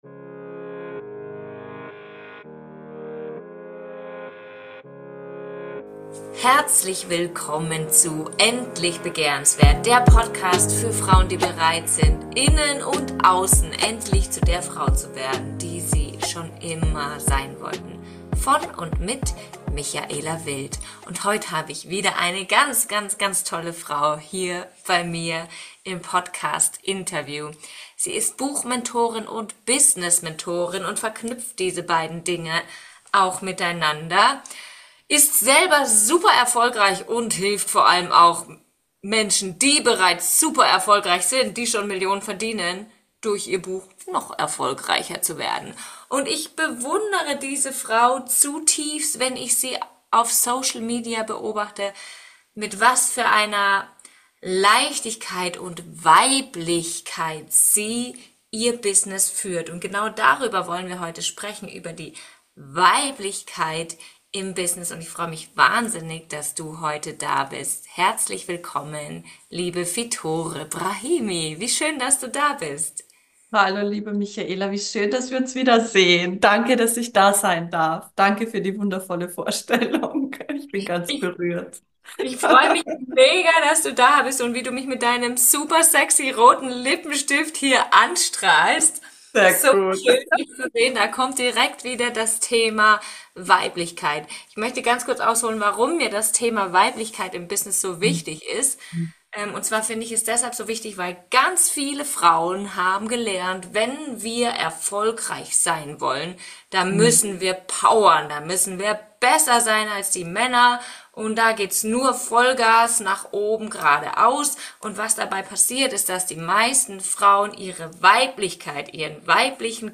Mach Dich gefasst auf ein spannendes Interview mit vielen Erkenntnissen.